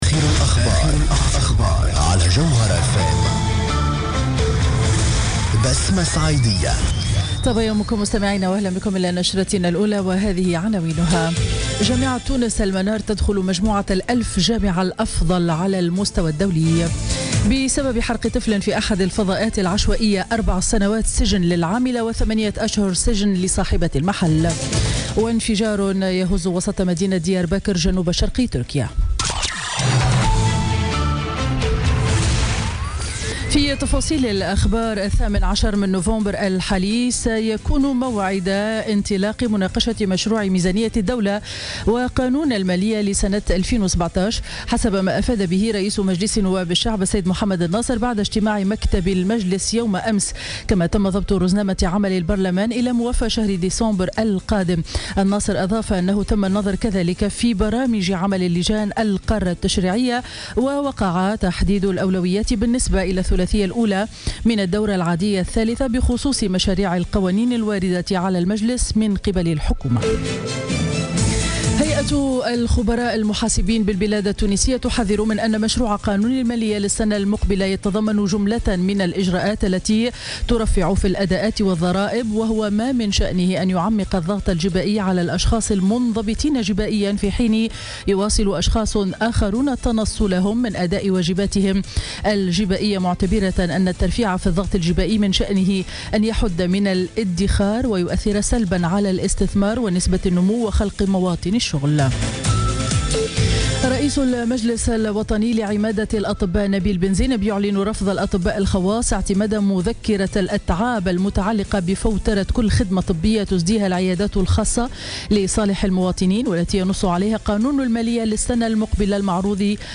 Journal Info 07h00 du vendredi 4 novembre 2016